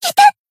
贡献 ） 分类:蔚蓝档案语音 协议:Copyright 您不可以覆盖此文件。
BA_V_Tomoe_Battle_Damage_3.ogg